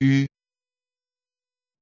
u (=piemontese); eu (=œ); j (=toscano es:girare).
laupiemontese.mp3